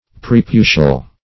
preputial - definition of preputial - synonyms, pronunciation, spelling from Free Dictionary Search Result for " preputial" : The Collaborative International Dictionary of English v.0.48: Preputial \Pre*pu"tial\, a. (Anat.) Of or pertaining to the prepuce.
preputial.mp3